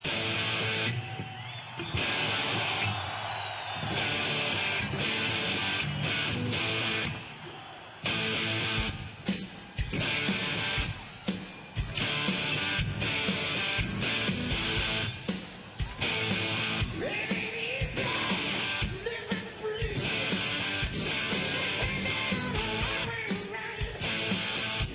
Rock Intros